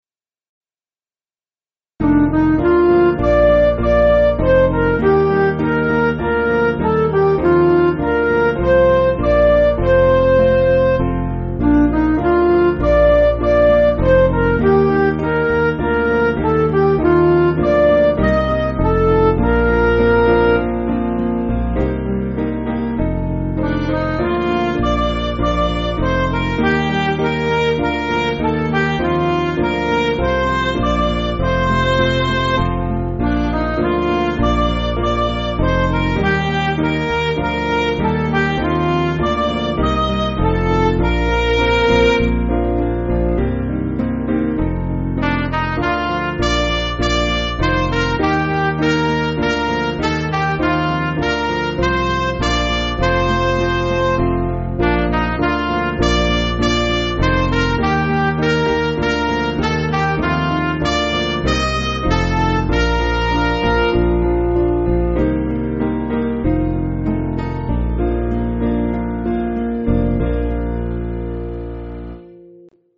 Piano & Instrumental
(CM)   3/Bb